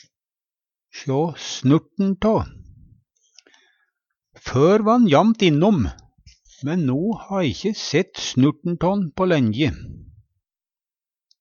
DIALEKTORD PÅ NORMERT NORSK o sjå snurt'n tå å sjå litt av nokon/noko Eksempel på bruk Før va'n jamt innom, men no har e ikkje sett snurt'n tå'n på lenje. Hør på dette ordet Ordklasse: Uttrykk Attende til søk